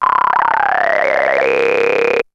E T TALKS 1.wav